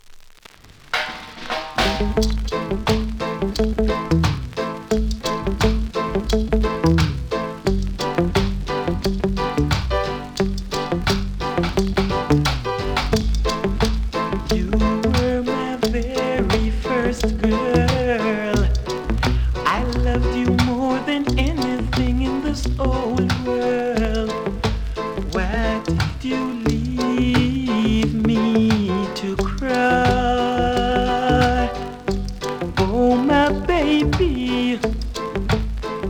両面プレス起因だと思われますがチリチリ・ノイズあり。